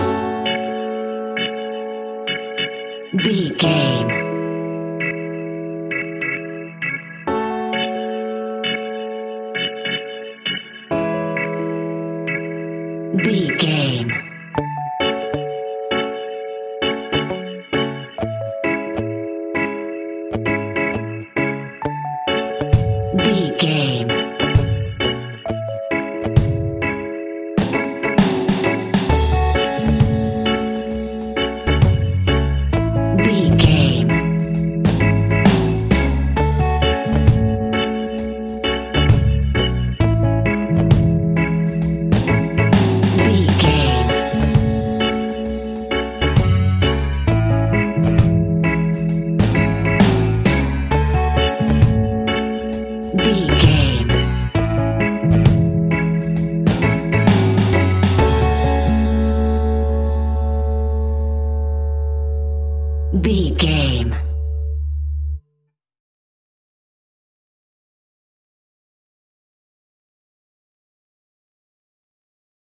A chilled and relaxed piece of smooth reggae music!
Aeolian/Minor
F#
off beat
drums
skank guitar
hammond organ
percussion
horns